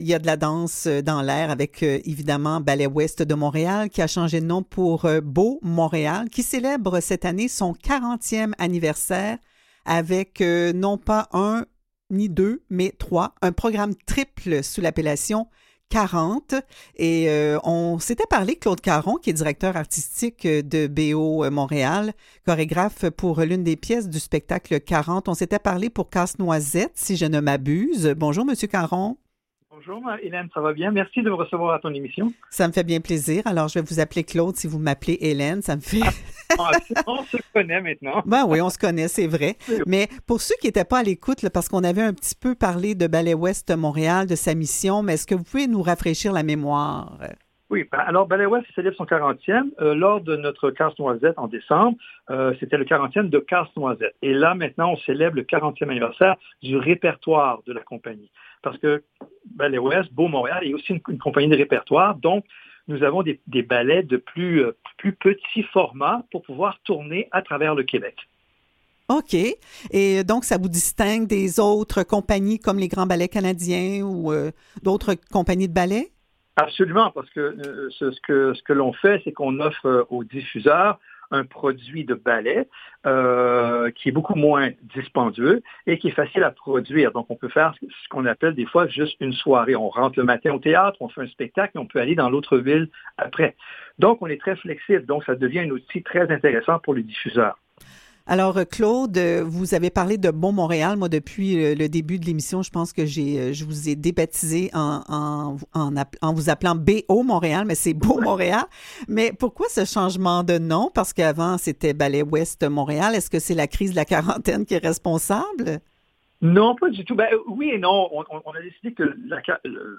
vous informe et commente l’actualité, entourée de collaborateurs et d’invités, en accordant une attention particulière à l’inclusion sociale. Au programme aujourd’hui : BO Montréal (Ballet Ouest de Montréal) présente un spectacle pour célébrer son 40e anniversaire.